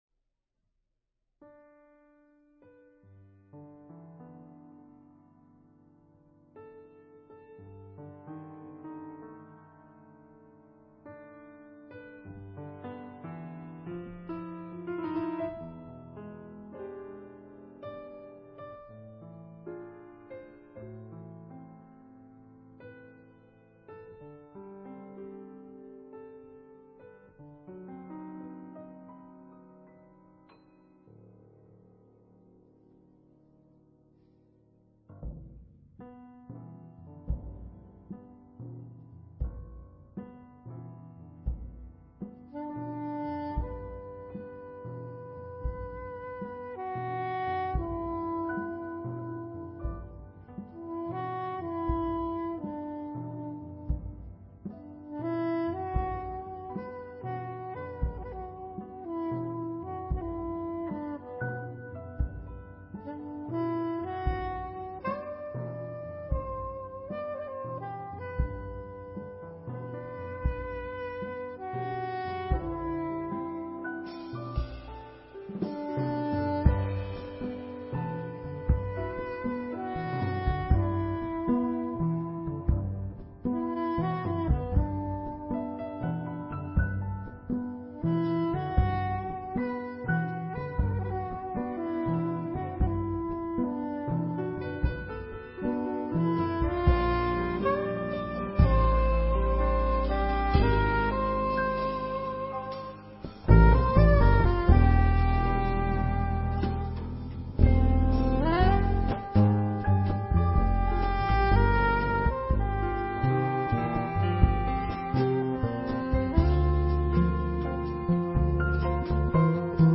claviers / piano
saxophone
contrebasse
batterie et guitare